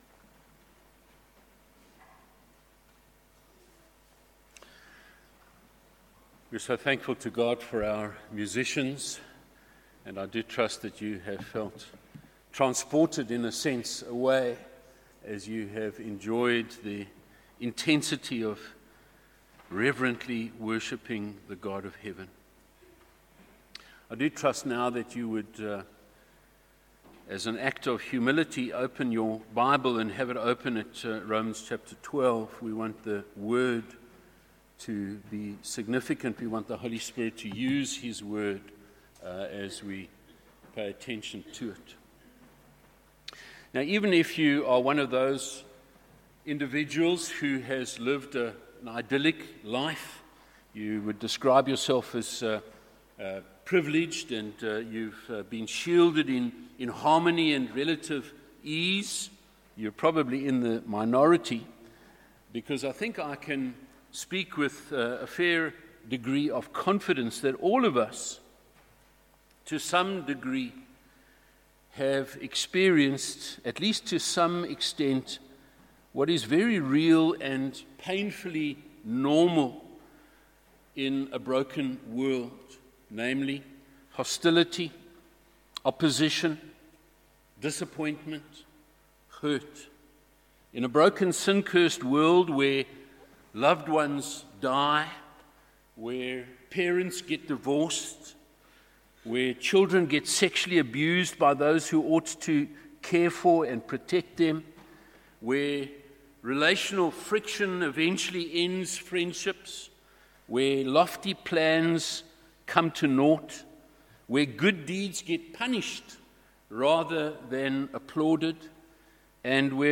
A message from the series "Romans."